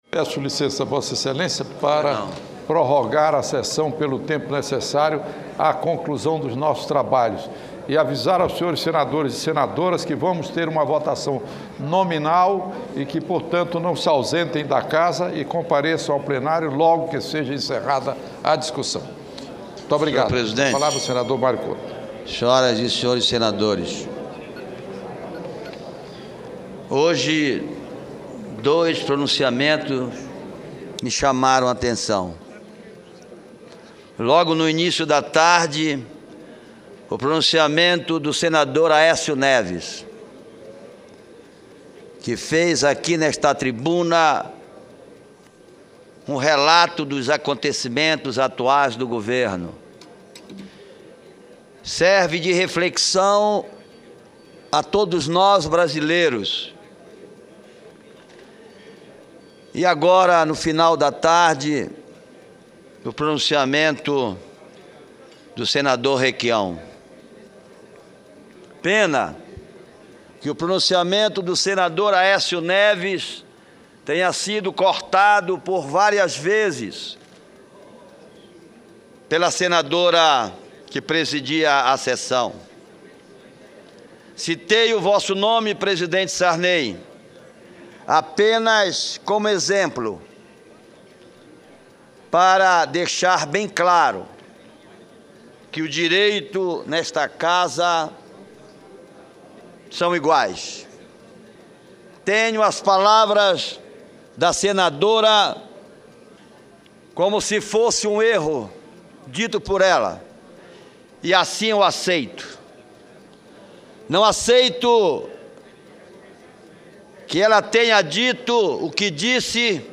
Pronunciamento do senador Mário Couto